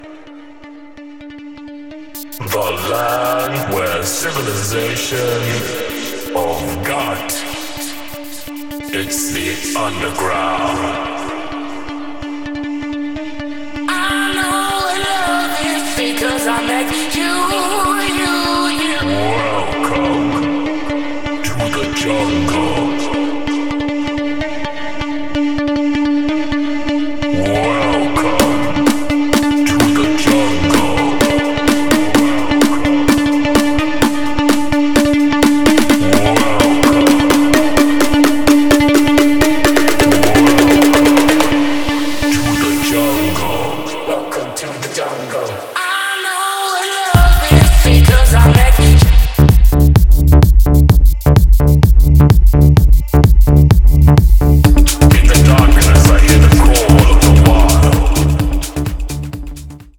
vibrant tech house tracks
dynamic and danceable tracks and remixes
DJ